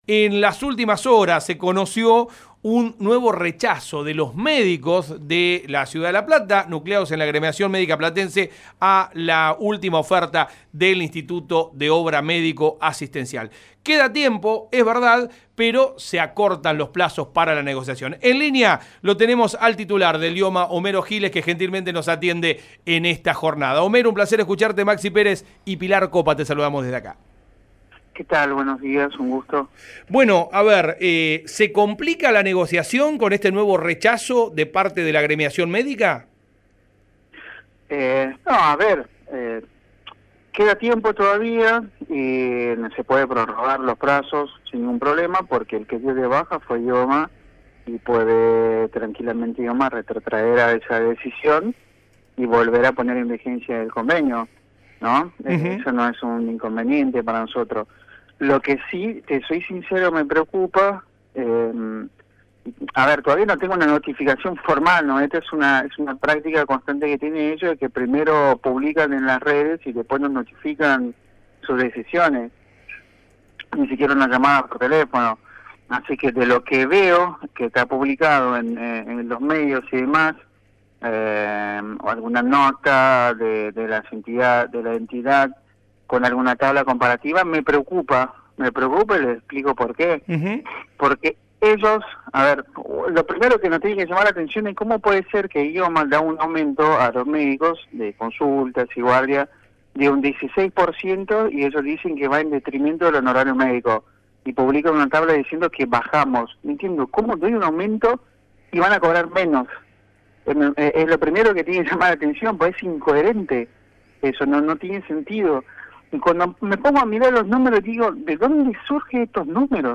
Homero Giles habló en “Código Baires”, por Radio La Plata 90.9.